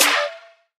• Trap Snare D Key 97.wav
Royality free steel snare drum sample tuned to the D note.
trap-snare-d-key-97-2Aj.wav